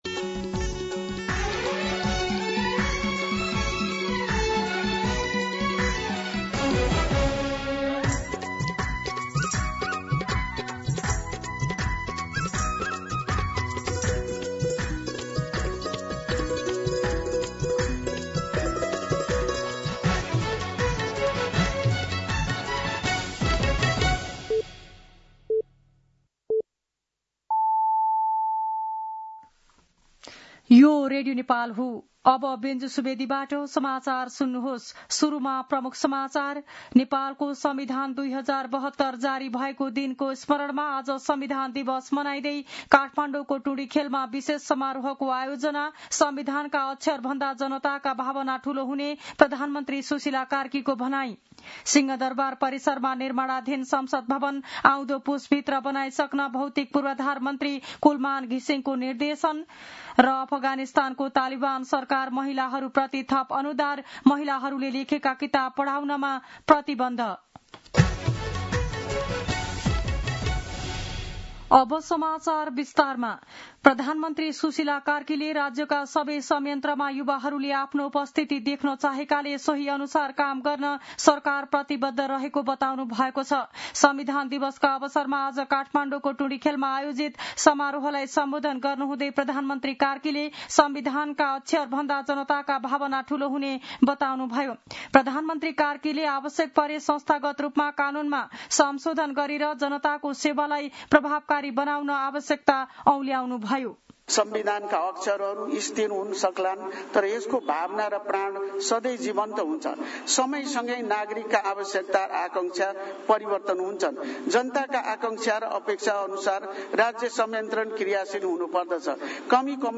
दिउँसो ३ बजेको नेपाली समाचार : ३ असोज , २०८२
3-pm-Nepali-News-2.mp3